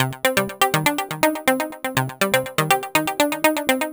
Simprog Seq C 122.wav